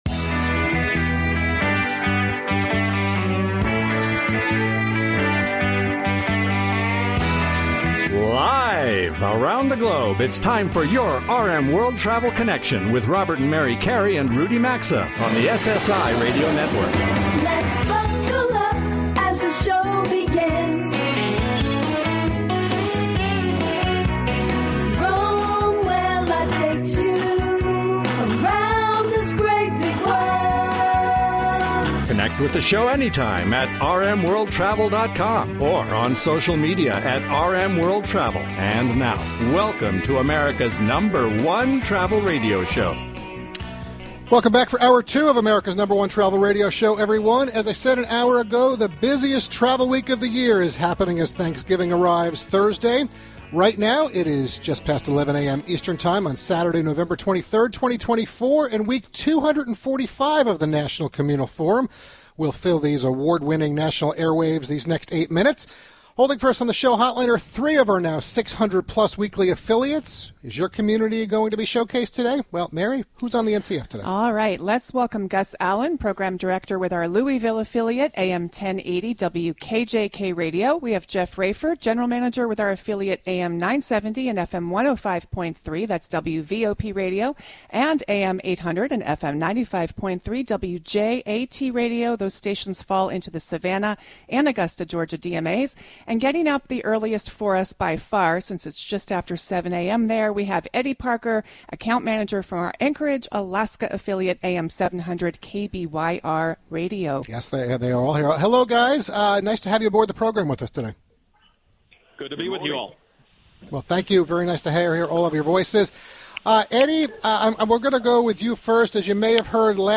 They provide straight-forward advice and commentary, inside scoop, tips/trends and more, as they connect with the audience and skillfully cover the world of travel, culture, and its allure through modern segments, on-location remote broadcasts, on-air showcasing and lively banter.